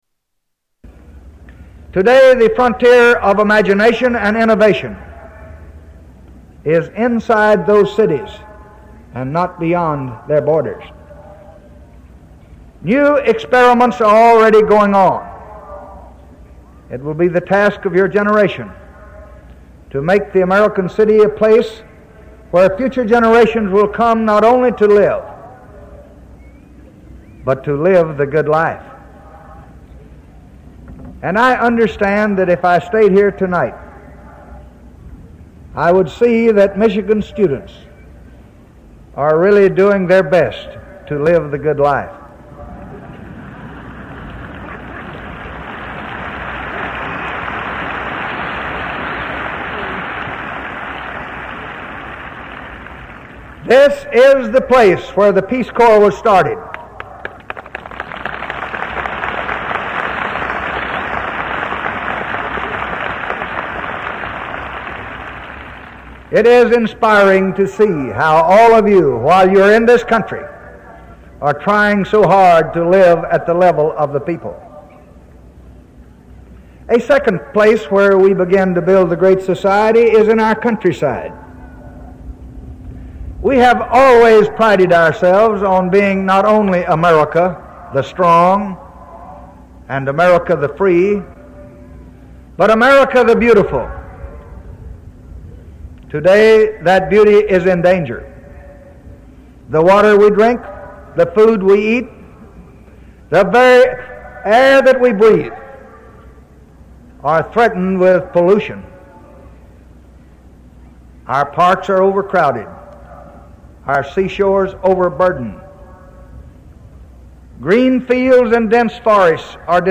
Tags: Historical Lyndon Baines Johnson Lyndon Baines Johnson clips LBJ Renunciation speech